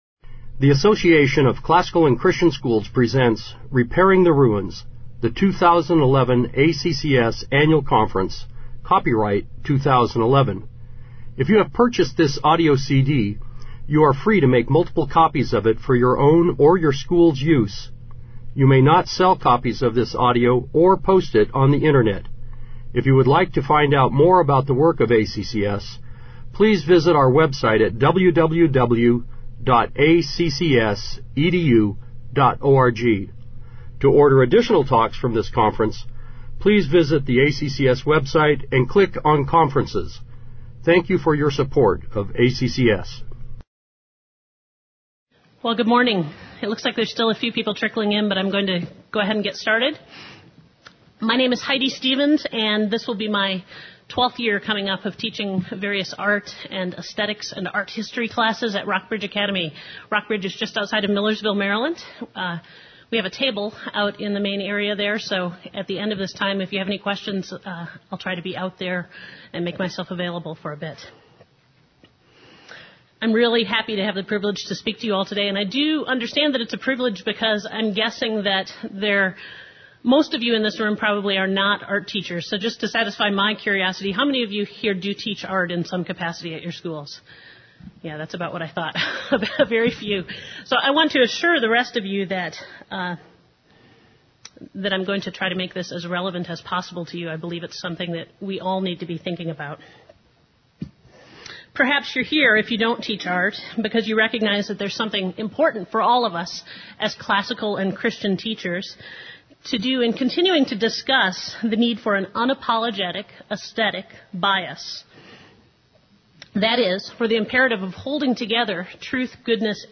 2011 Workshop Talk | 1:01:27 | All Grade Levels, Art & Music, General Classroom